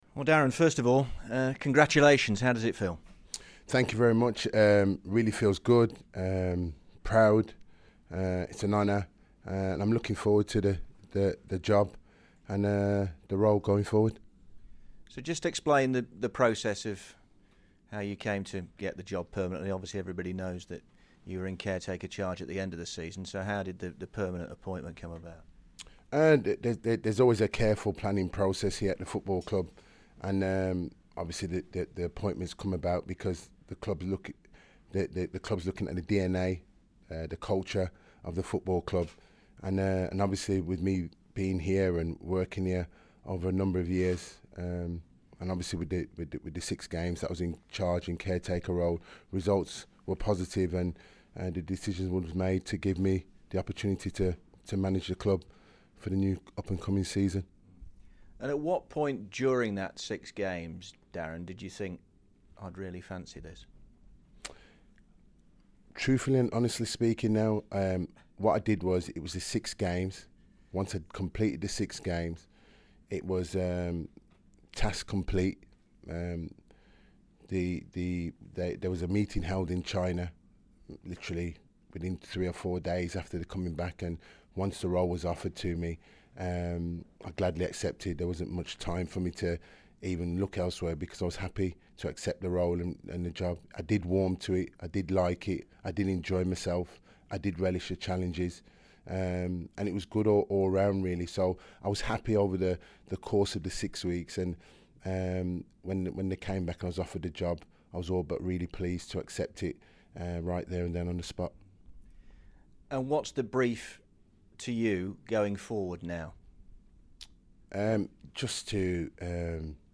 in his first media interview since being confirmed in the job full time.